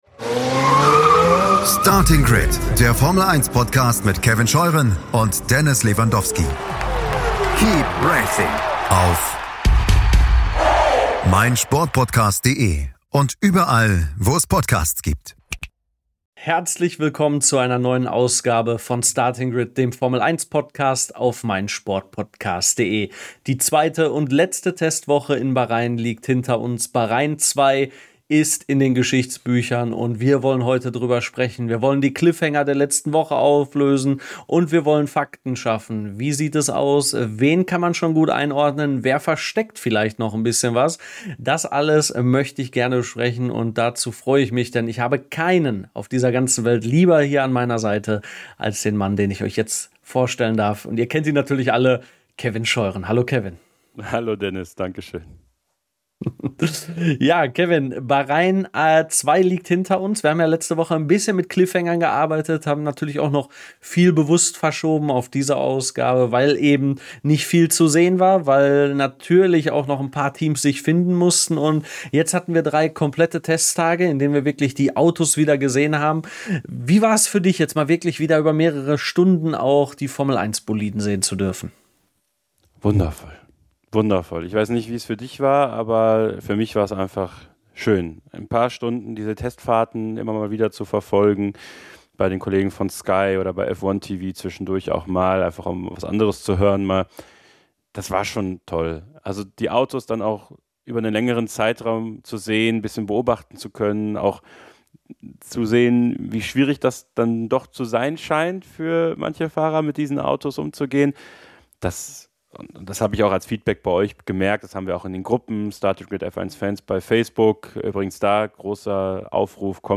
Lando Norris konnte den F1-Saisonauftakt gewinnen, aber es gibt so viele Themen, die wir u.a. mit RTL-Experte Günther Steiner in der neuen Ausgabe besprechen.